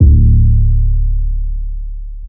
Metro 808s [West].wav